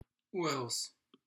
How to pronounce Uruz